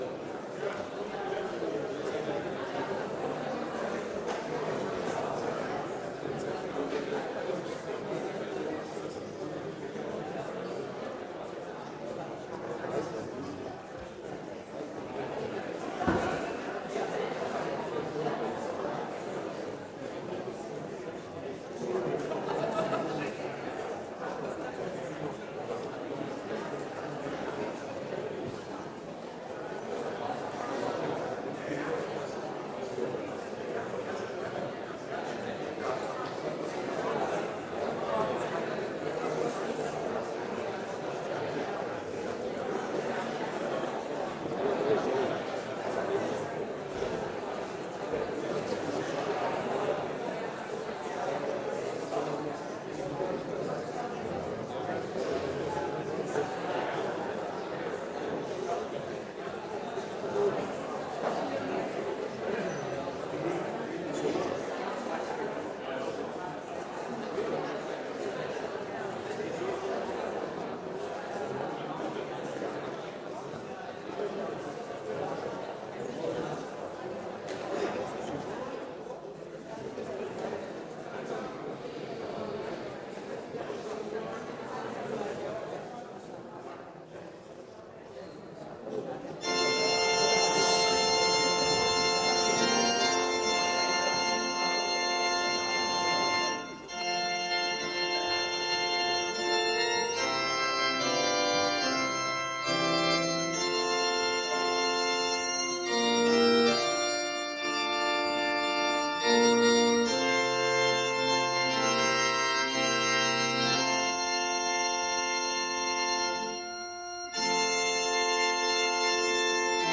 Gottesdienst vom 18. Juni